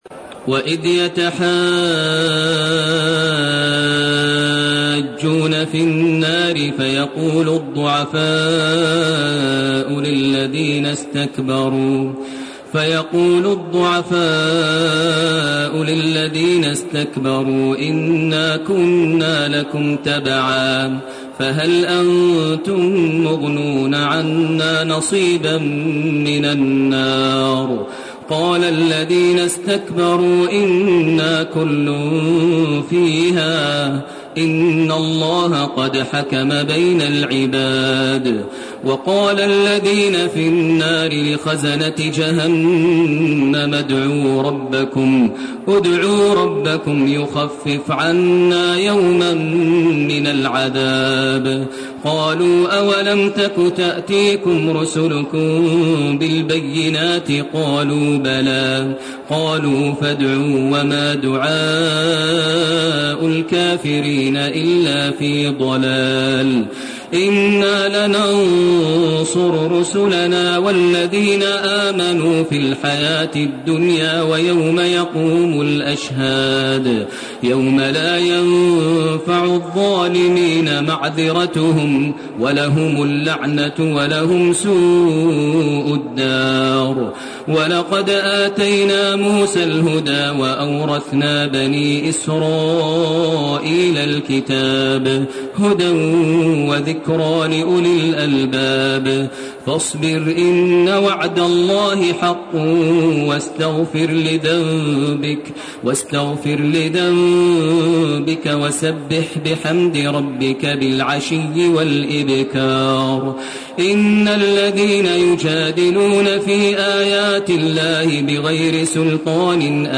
سورة غافر 47 الي اخرها وسورة فصلت من 1 الي 46 > تراويح ١٤٣٢ > التراويح - تلاوات ماهر المعيقلي